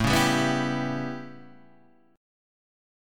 A9 chord {5 4 5 4 5 3} chord